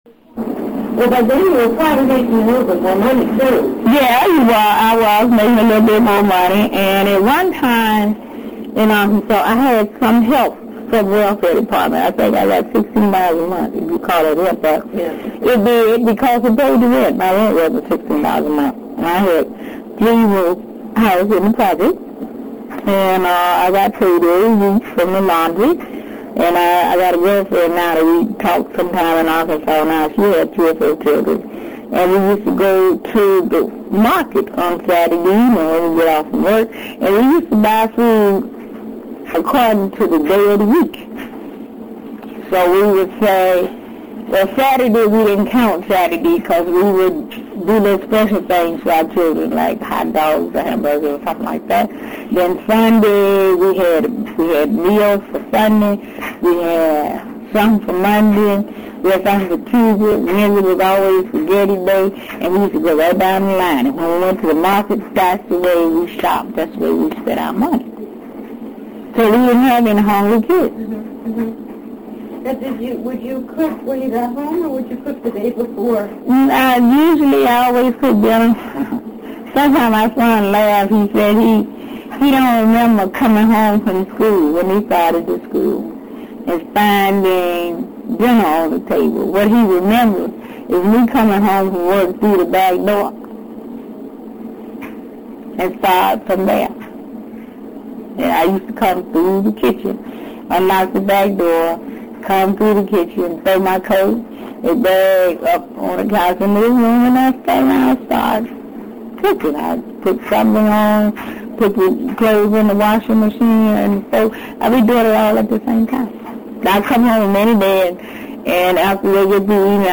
Like the subsequent interviews in this 1991 oral history, the interview was conducted in her home in the Watts/Willowbrook neighborhood of south Los Angeles. Tillmon was warm and friendly and rapport was easily established with her.
Electrical interference in the house created problems in the audio quality of the interview, resulting in feedback and background static particularly in the first two tapes sides. Periodically, the static cuts off the audio for short intervals of time. The audio quality improves during the third tape and there are very few problems with the fourth tape.